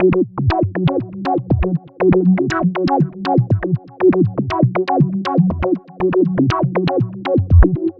Body Music F# 120.wav